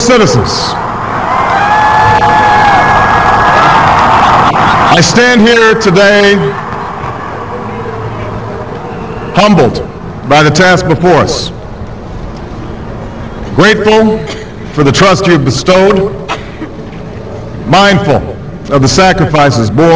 Skype accepts only this format: PCM, mono, 16 bit, 16 kHz.
Sample recording for Skype:
obama_skype.wav